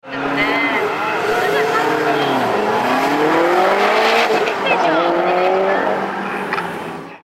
・かなりイケてる愛車のエンジンの音(笑)
”ジェット機みたいな凄い音してますよ”
静かでイケてる音がするのでお勧めです。
ジムカーナ練習会での発進加速音(mp3形式)